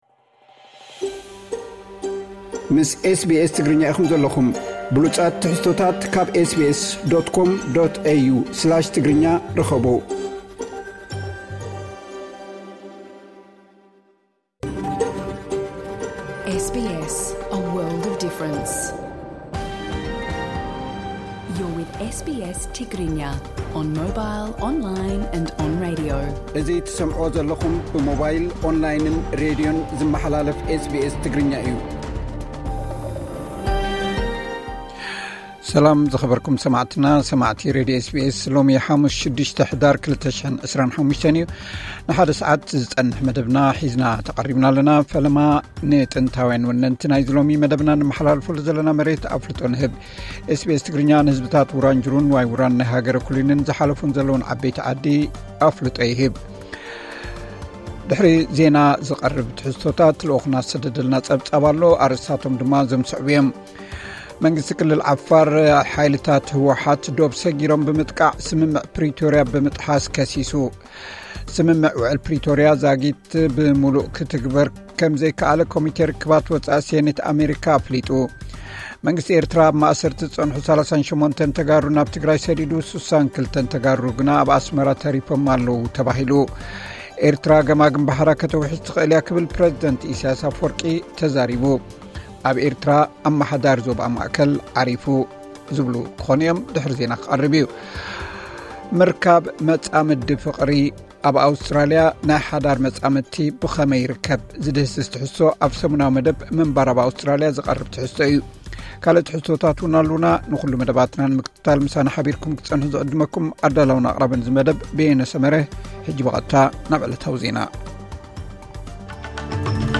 ቀጥታ ምሉእ ትሕዝቶ ኤስ ቢ ኤስ ትግርኛ (06 ሕዳር 2025)